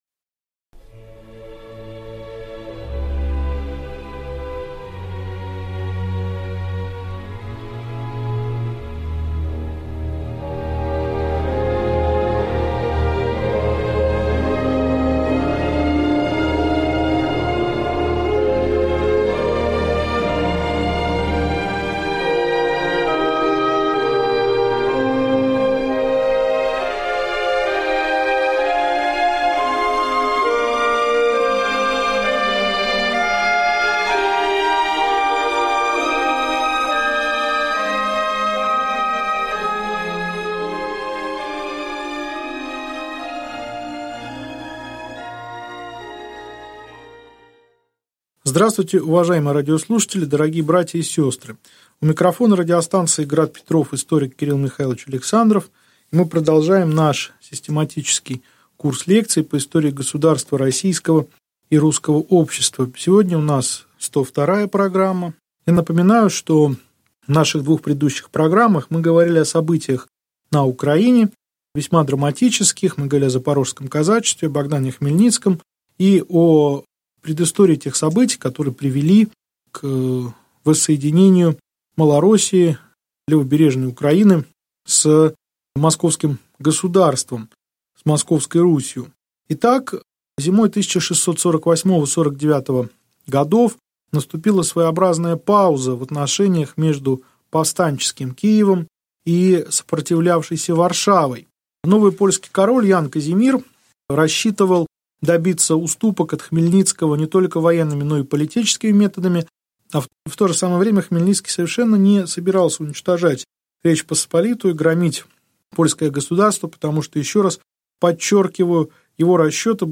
Аудиокнига Лекция 102. Присоединение Украины к России | Библиотека аудиокниг